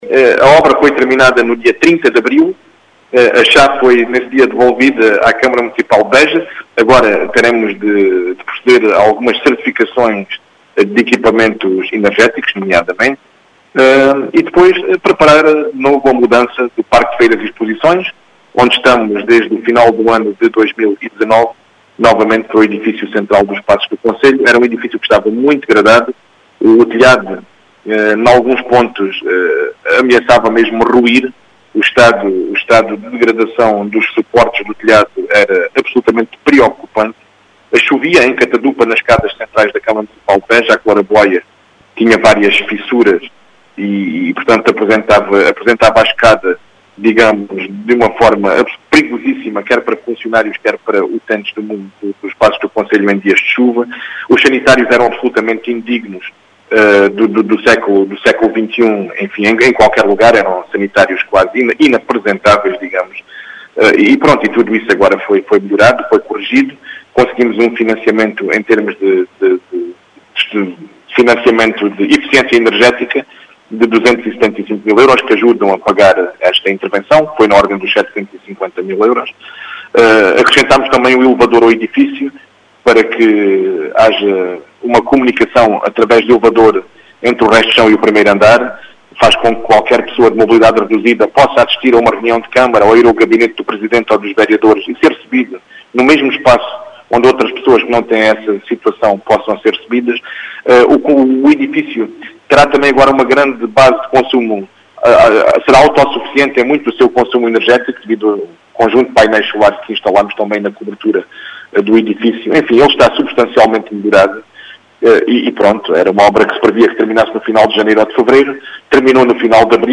As explicações foram deixadas por Paulo Arsénio, aos microfones da Rádio Vidigueira.